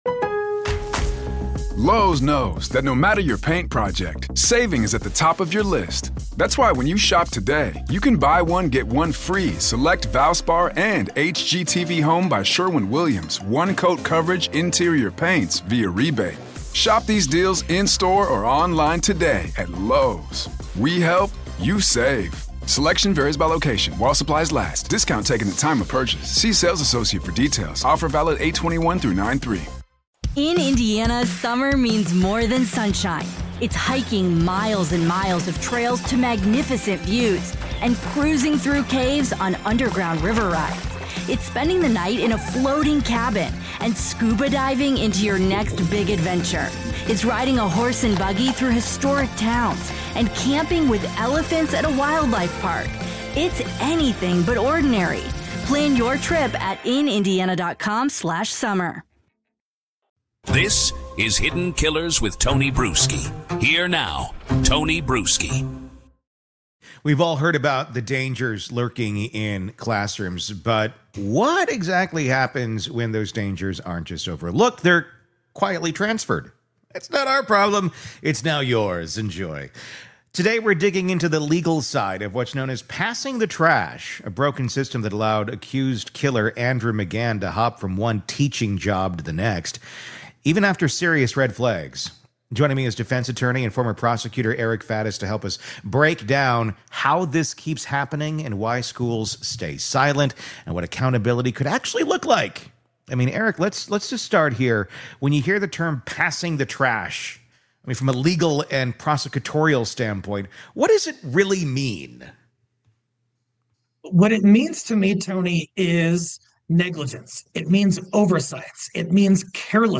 In this full-length conversation